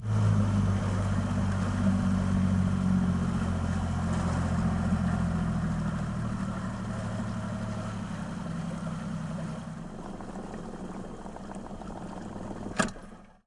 快艇舷外马达，印度尼西亚提多，印度尼西亚
描述：双人雅马哈舷外发动机在公共快艇上咆哮，从Pulau Tidore到Ternate，Spice Islands，Indonesia PCMD50
Tag: 蒂多雷 轮渡 载体 海洋 航运 特尔纳特 印度尼西亚 现场录音 交通 发动机 热带 柴油 快艇 速度 船舶 电机 螺旋桨 亚洲外 雅马哈